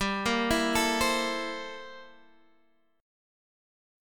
C13/G Chord
Listen to C13/G strummed